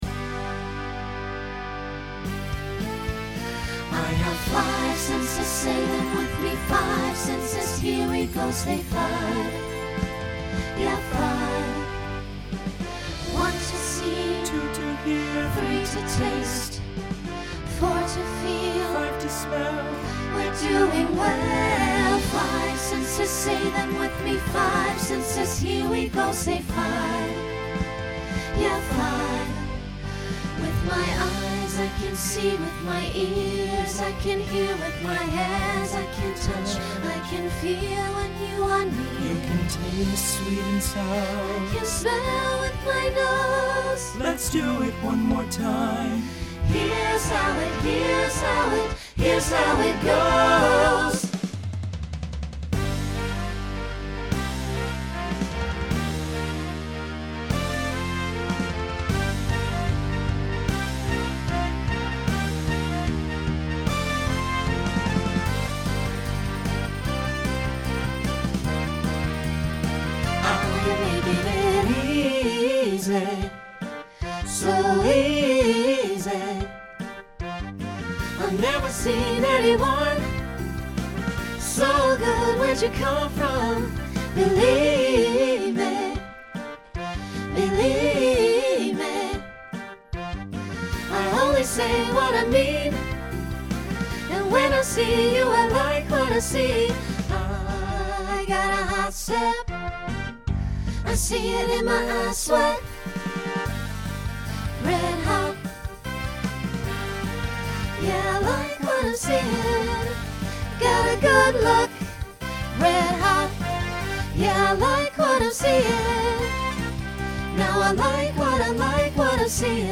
Genre Pop/Dance , Rock
Voicing SAB